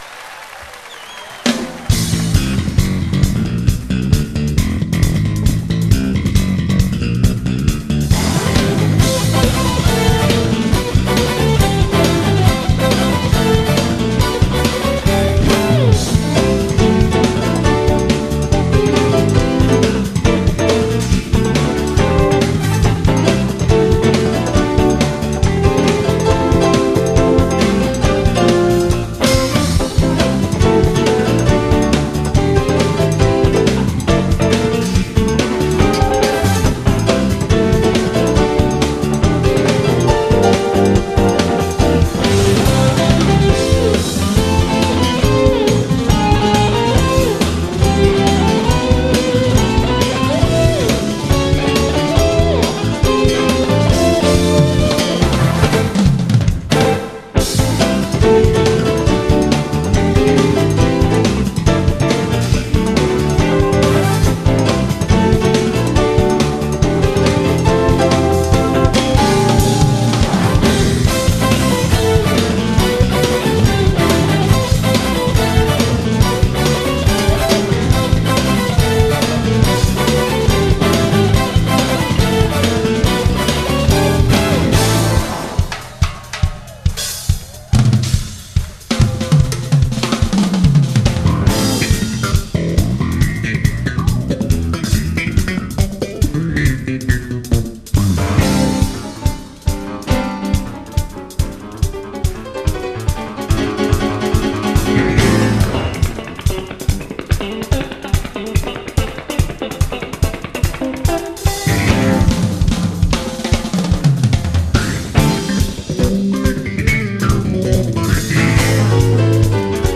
-굉장히 빠르군요...